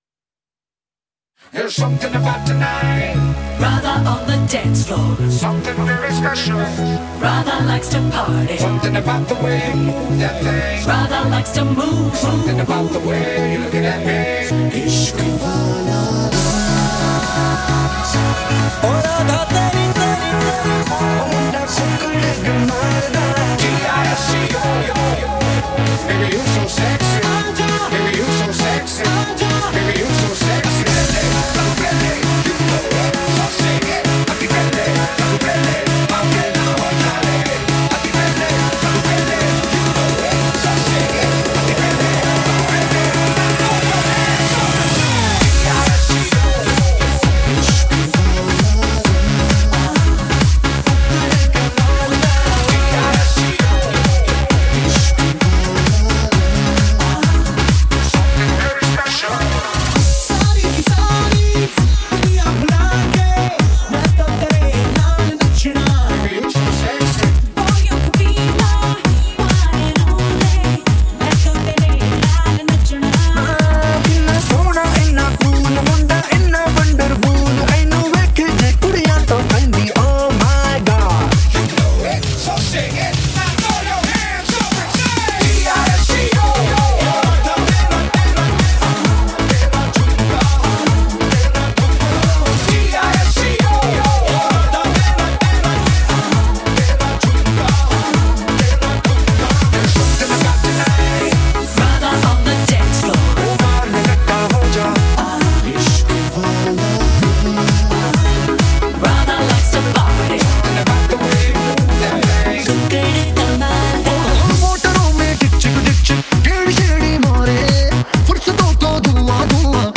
.mp3 Song Download Bollywood Mazafree